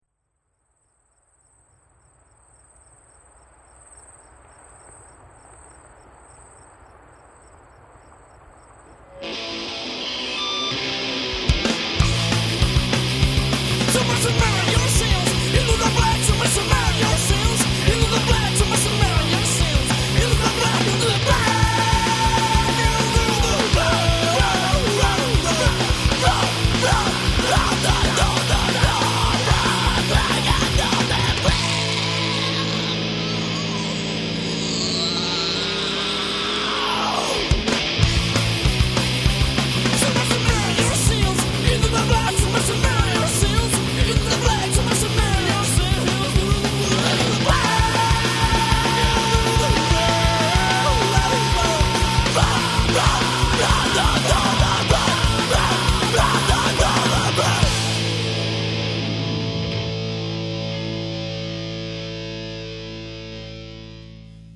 guitarra
bajo
bateria